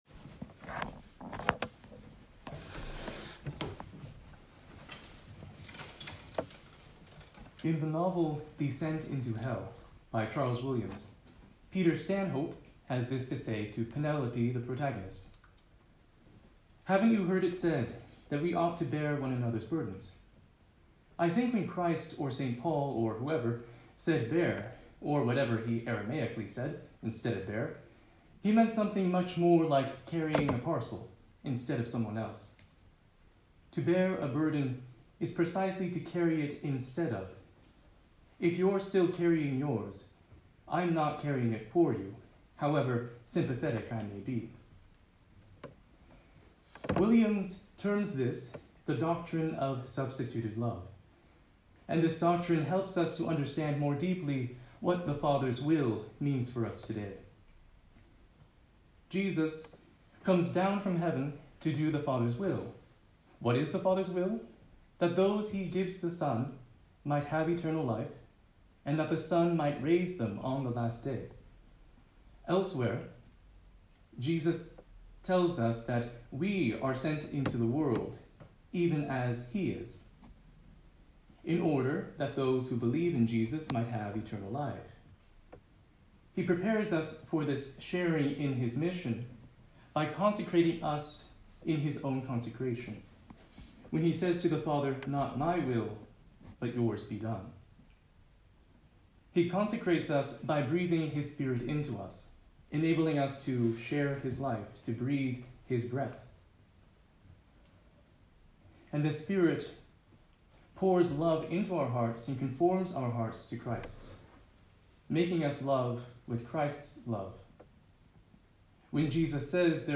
The following homily was preached to the student brothers during compline.